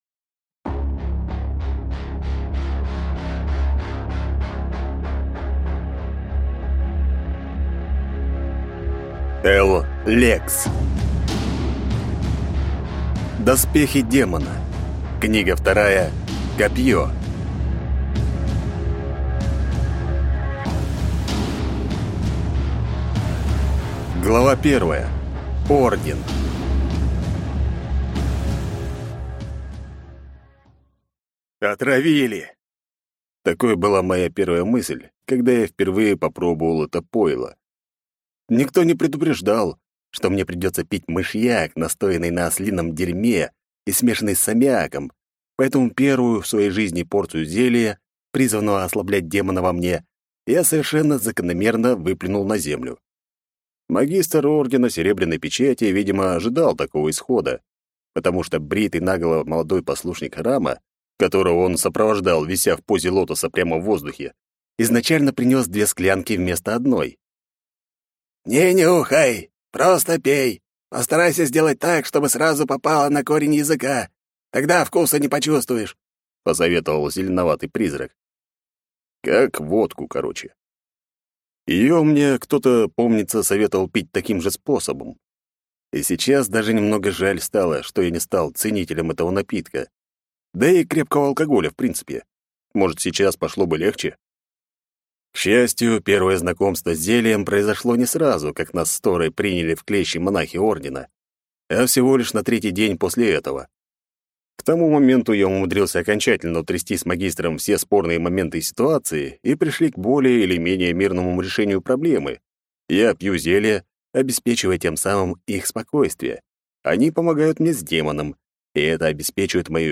Аудиокнига Доспехи демона. Книга 2. Копье | Библиотека аудиокниг